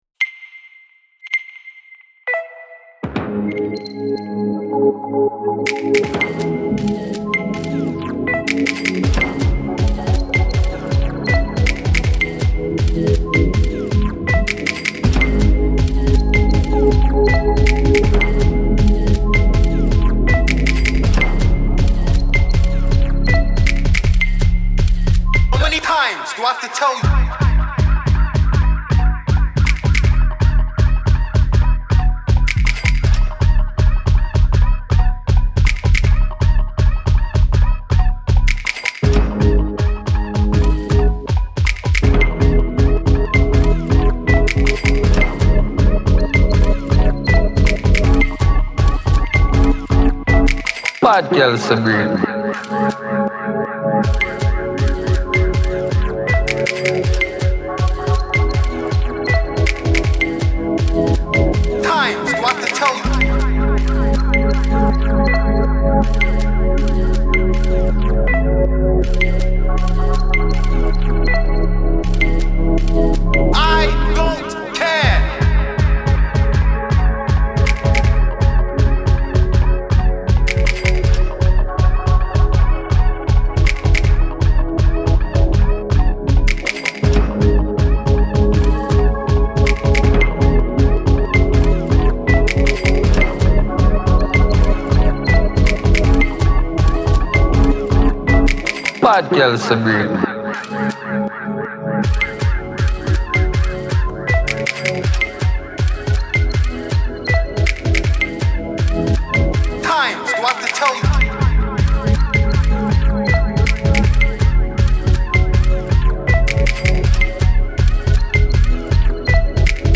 Play the beats below – created by young people and see how the visual image above responds to their sound!